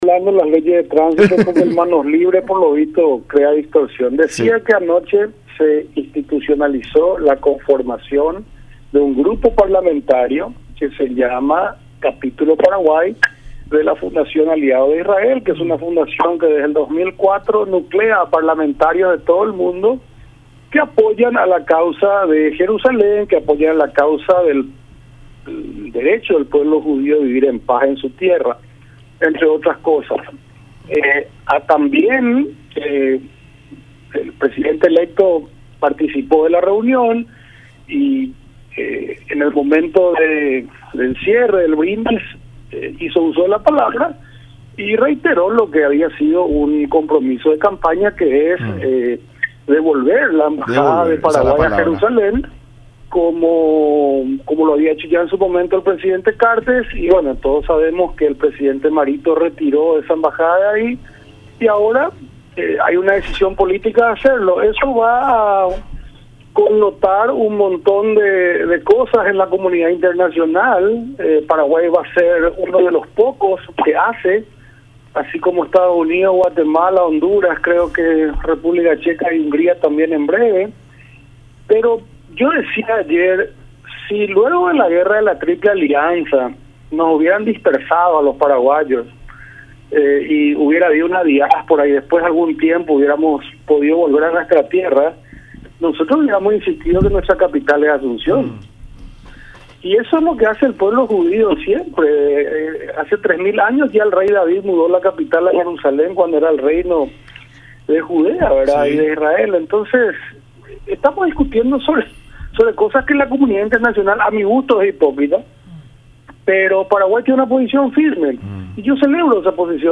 “Debemos protestar en todos los ámbitos, trabajar con todos los amigos que podamos, embarcar a Brasil y Bolivia, poner toda la presión internacional a Argentina y hacerles ver que están equivocados”, declaró Gustavo Leite en el programa “La Mañana De Unión” por Radio La Unión y Unión Tv.